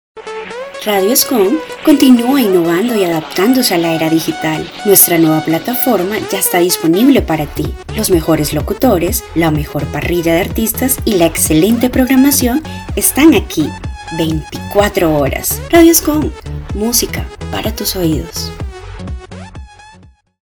kolumbianisch
Sprechprobe: Industrie (Muttersprache):